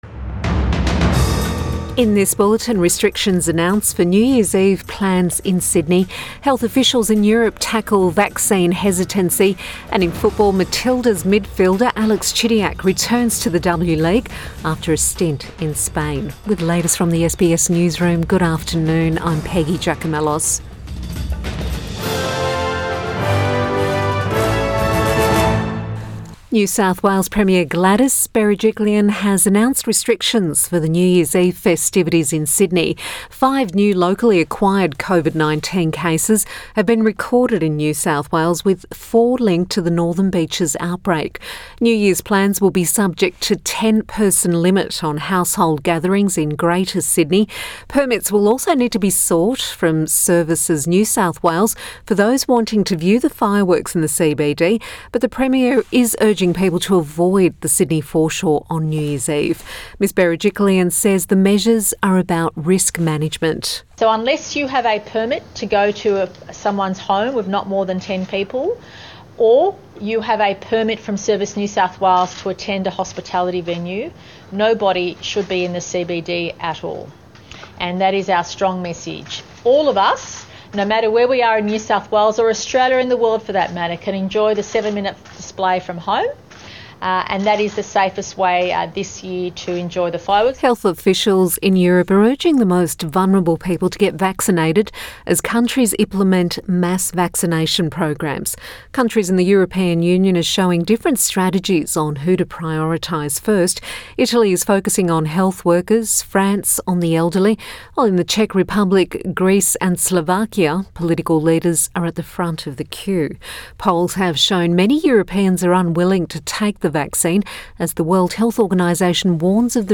Midday bulletin 28 December 2020